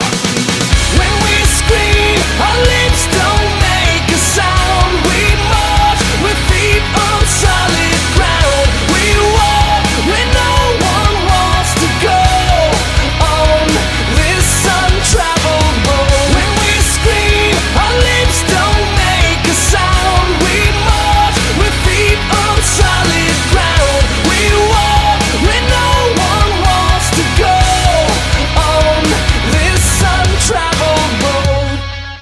• Качество: 192, Stereo
мужской вокал
громкие
мощные
Rapcore